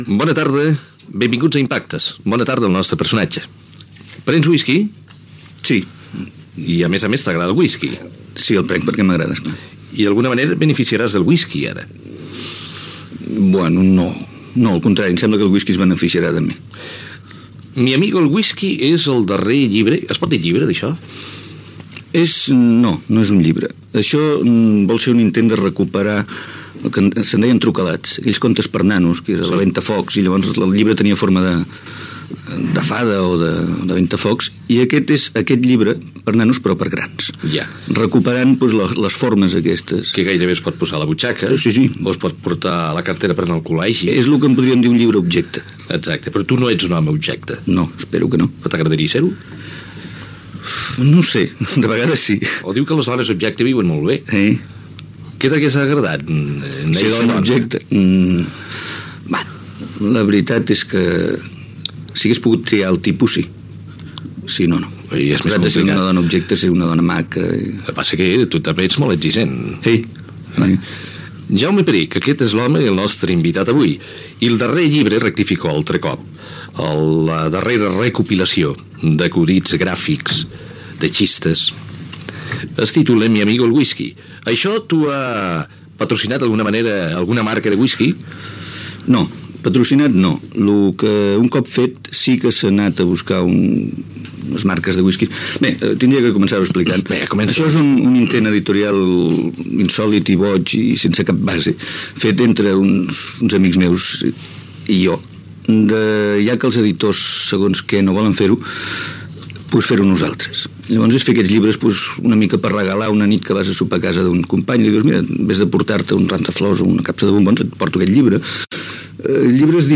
Entrevista al humorista Jaume Perich que havia publicat la recopilació d'acudits "Mi amigo el whisky".
Informatiu